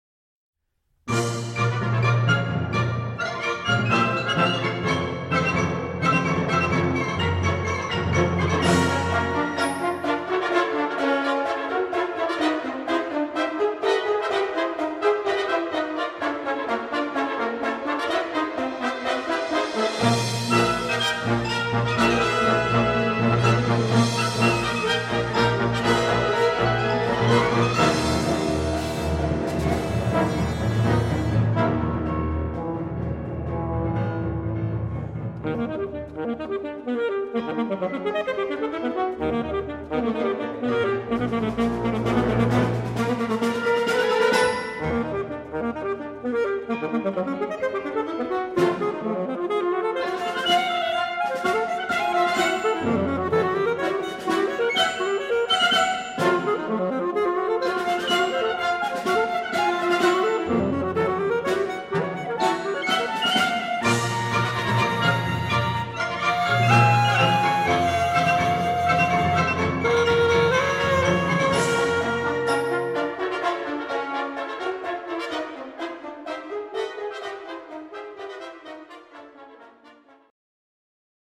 Instrumentation: E-flat alto saxophone and concert band
for alto saxophone and band